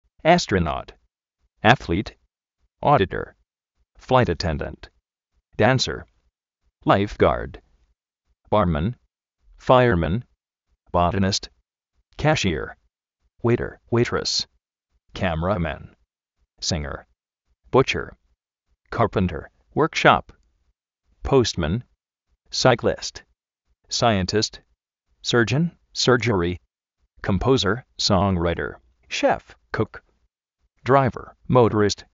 ástronot
azlít
fláit aténdant
uéiter, uéitres